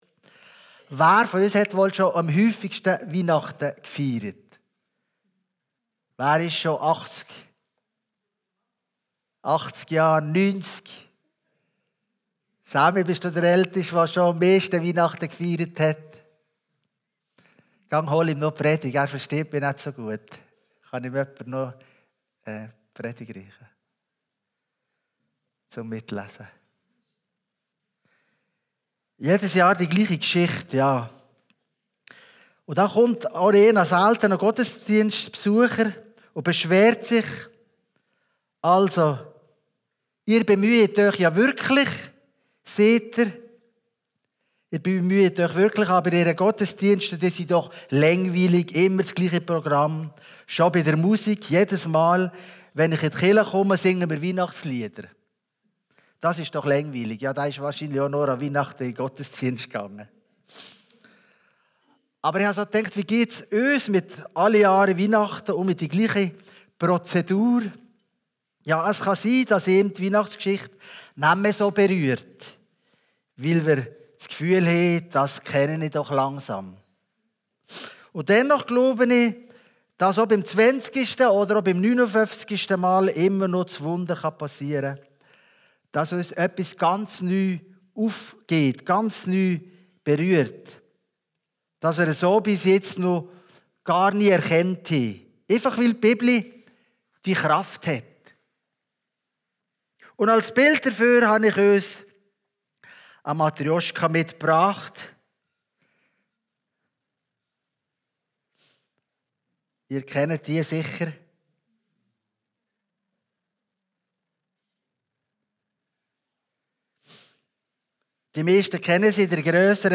Weihnachtspredigt › Chrischona Fünflibertal